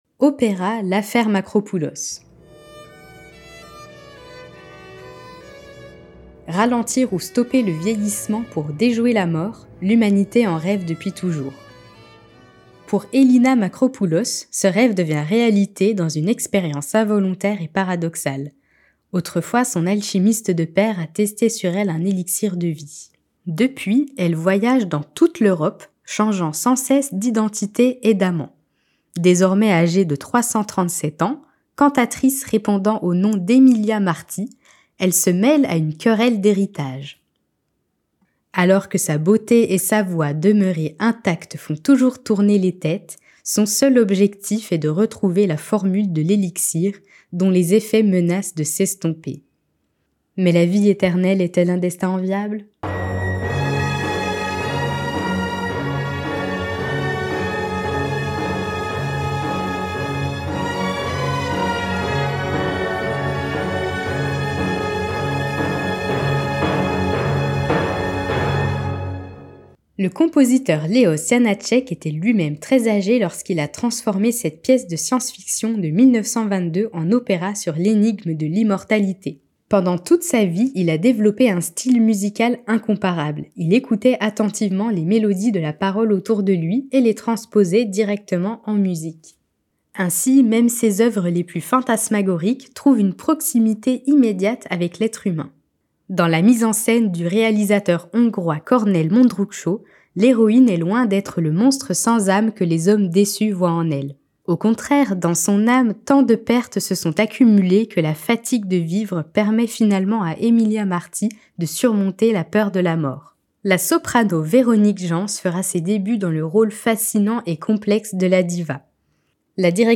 Lecture-audio-site-web-LAffaire-Makropoulos.mp3